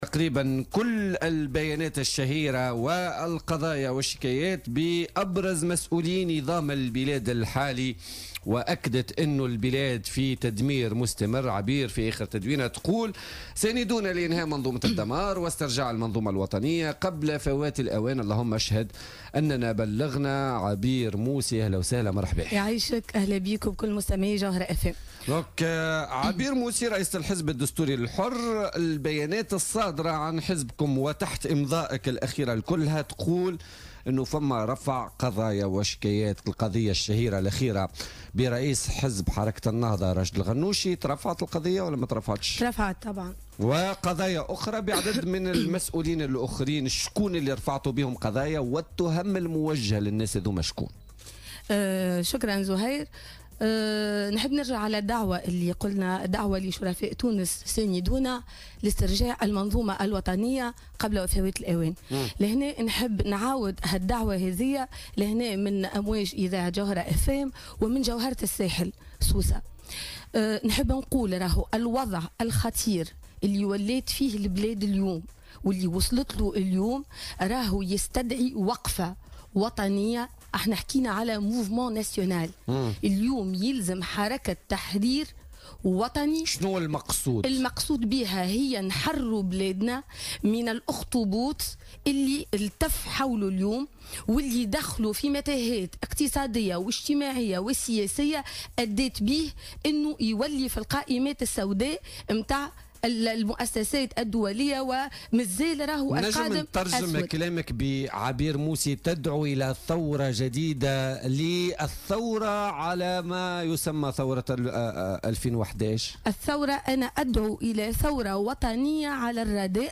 قالت عبير موسي رئيسة الحزب الدستوري الحر ضيفة بولتيكا اليوم الإثنين إن الوضع الخطير يستدعي وقفة وطنية لتحرير البلاد من الإخطبوط اللي التف حوله اليوم والذي أدخله في متاهات اقتصادية واجتماعية وسياسية أدت به إلى التصنيف في قائمات دولية سوداء.